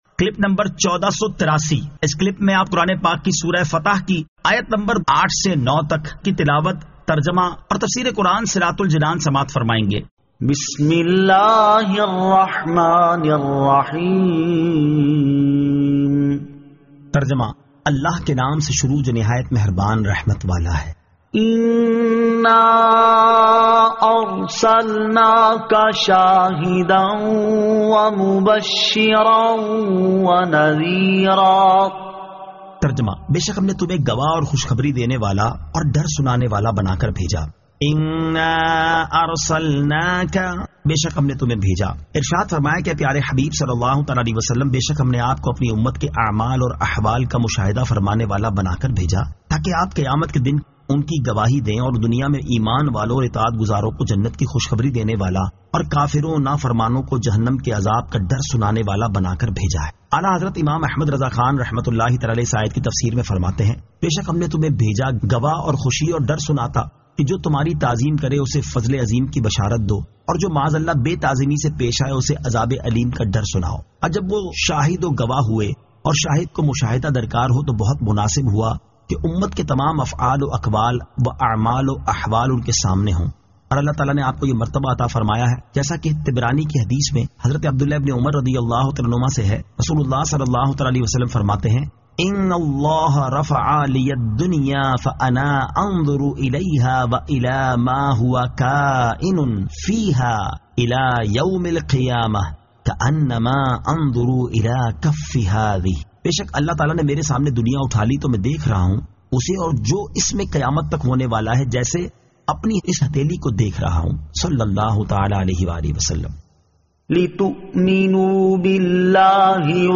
Surah Al-Fath 08 To 09 Tilawat , Tarjama , Tafseer